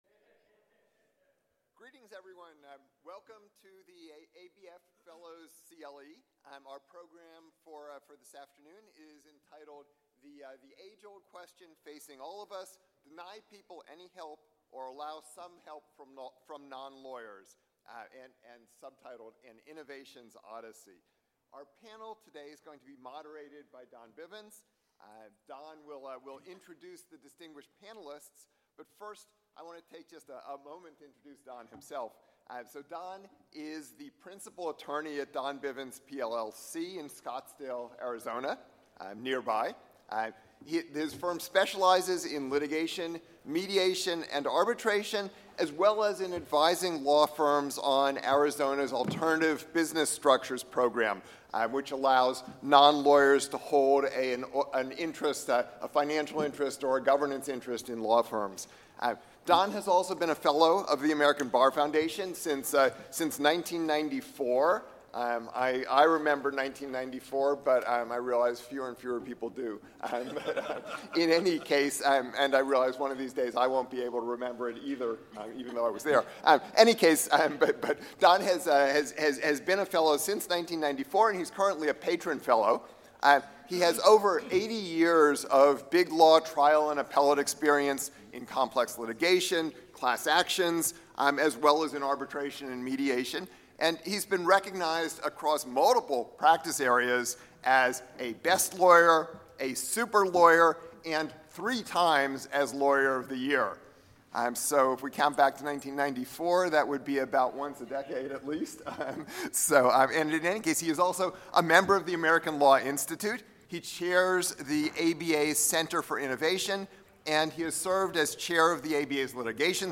At the 2025 ABA Midyear Meeting, legal experts gathered to debate a critical question: Should people facing civil legal issues be denied help entirely if they cannot afford a lawyer, or should trained non-lawyers be allowed to assist?